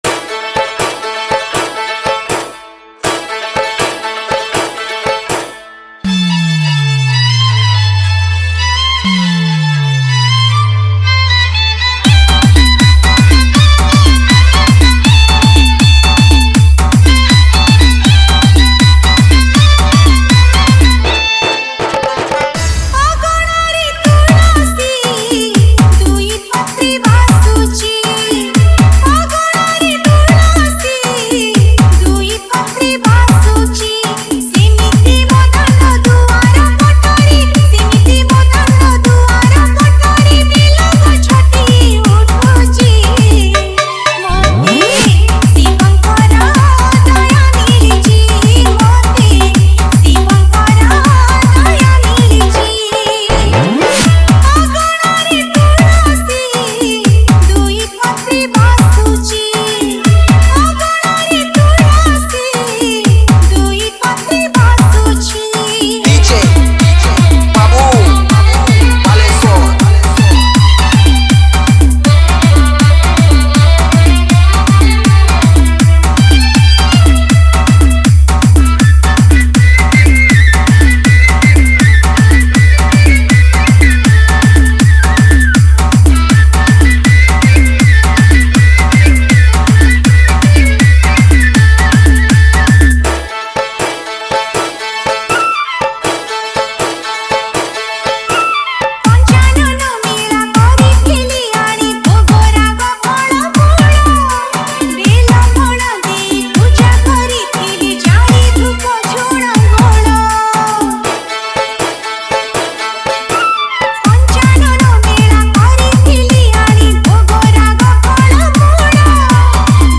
Category:  Odia Bhajan Dj 2025